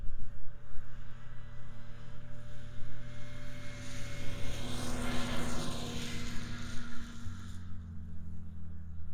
Internal Combustion Subjective Noise Event Audio File (WAV)